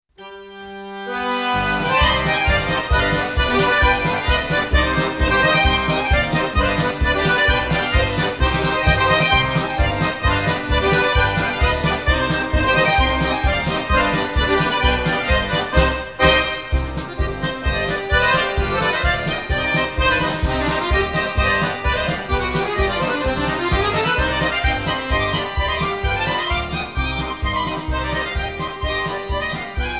Recorded: Airforce Recording Studios Limited